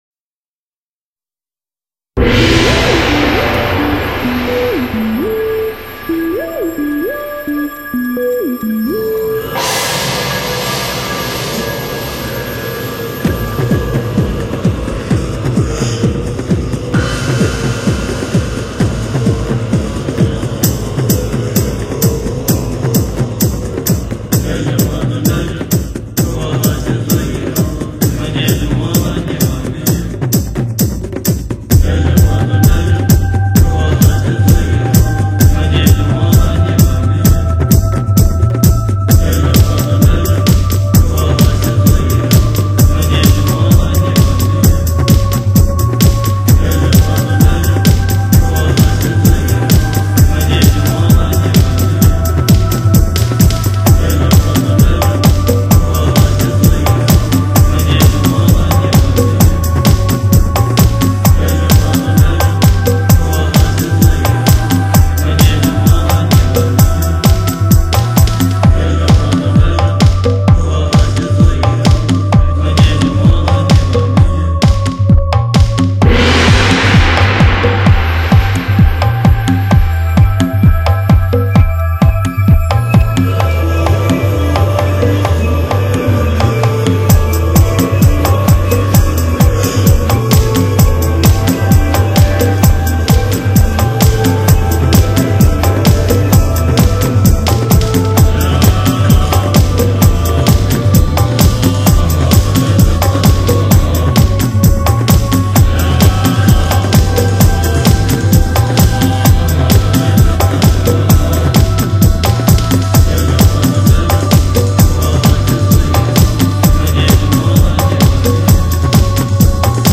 一张令你听后感到清新，宁静，忘我，释放的迷幻发烧音乐天碟。.
融合了中东迷幻与西藏的神秘感，穿插了佛教的宁静，加入了现在.
一流的录音效果，是今世纪不可错过的经典唱片，试音必备。